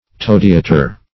Toadeater \Toad"eat`er\, n. [Said to be so called in allusion to